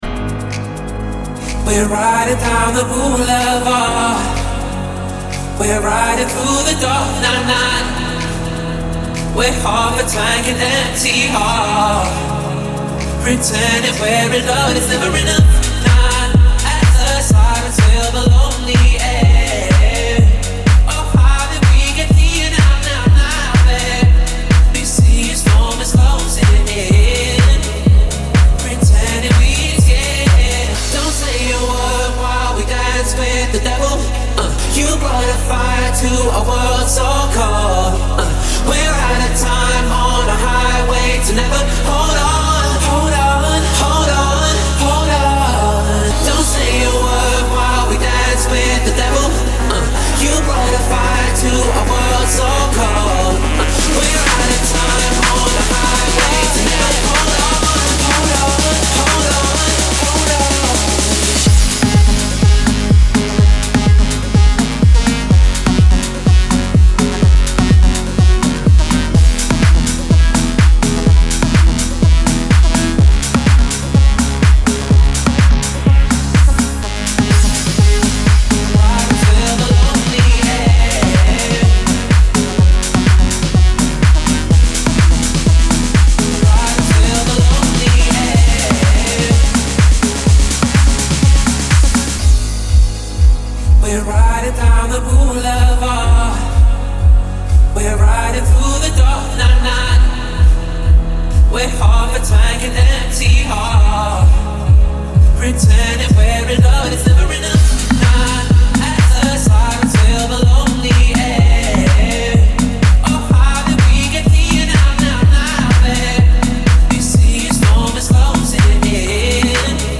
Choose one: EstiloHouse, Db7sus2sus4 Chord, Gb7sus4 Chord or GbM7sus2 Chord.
EstiloHouse